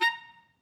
DCClar_stac_A#4_v2_rr2_sum.wav